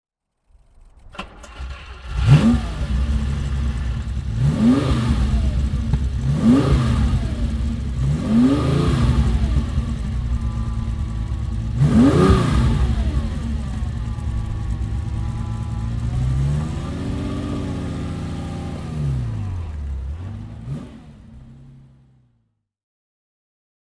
Ferrari 365 GTC/4 "Spider" (1971) - Starten und Leerlauf